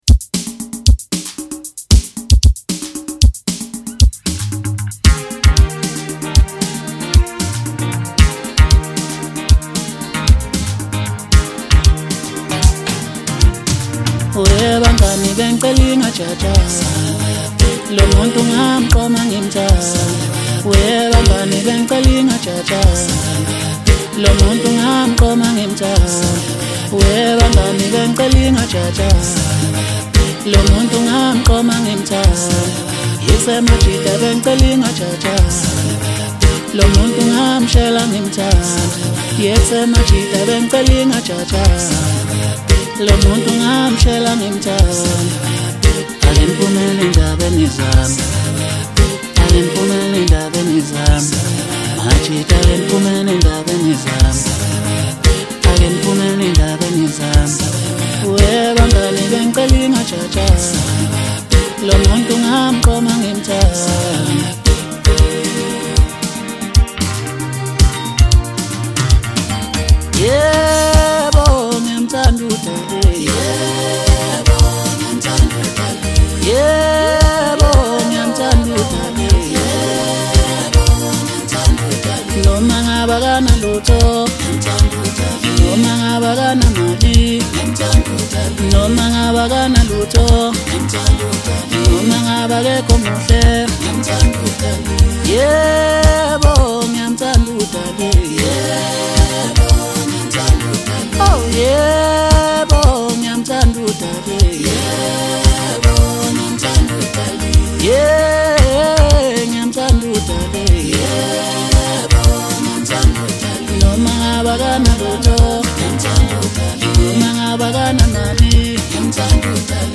Maskandi